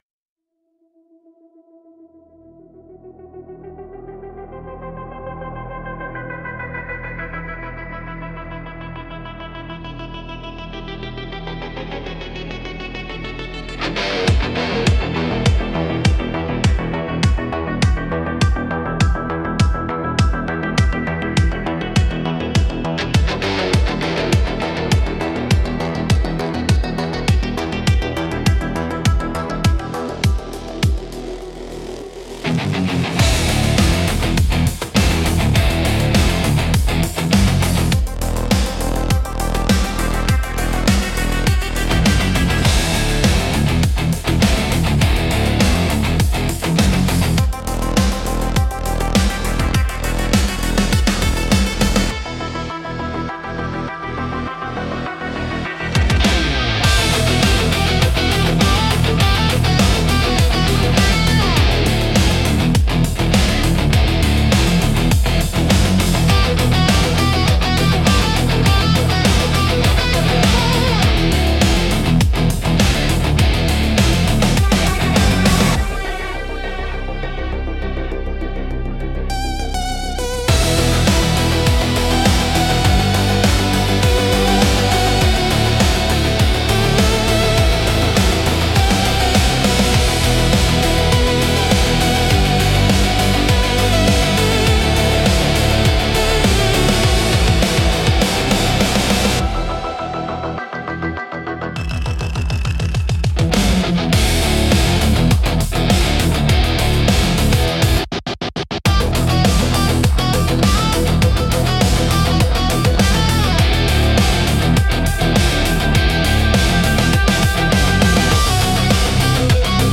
Instrumental - Last Call at the Last Exit